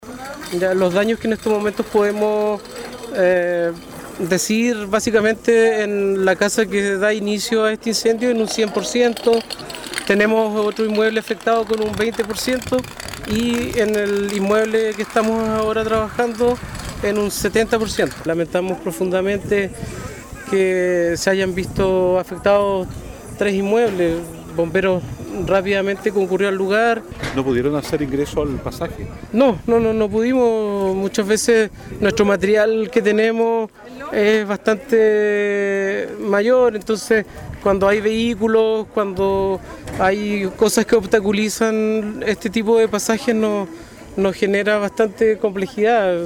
La destrucción fue total en el inmueble principal y en un porcentaje alto en las casas que se encontraban a los costados, detalló el oficial de Bomberos, puntualizando que por lo estrecho del pasaje, no pudieron hacer ingreso los carros, pero que ello no fue un factor para el ataque a las llamas.